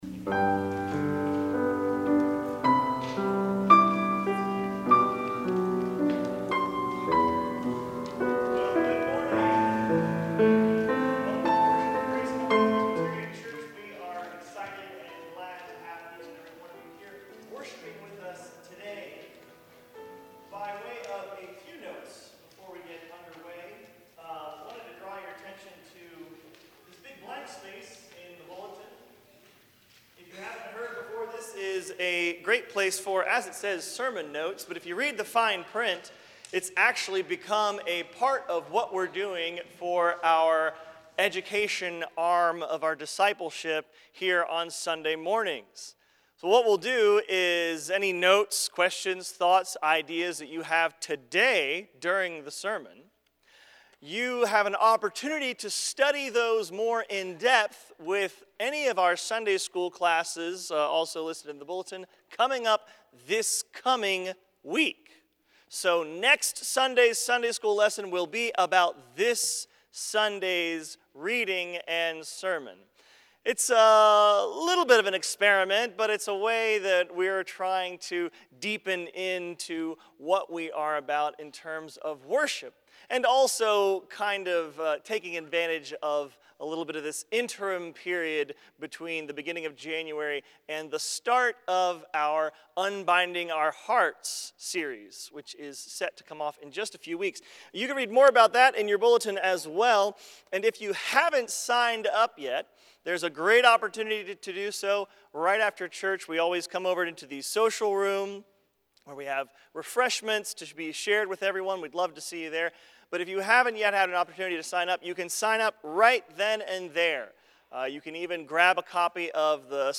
Full Services - Grace Covenant Presbyterian Church
PRELUDE Andante sostenuto (Symphonie gothique, No. 9, Op. 70) (Charles-Marie Widor, 1844-1937)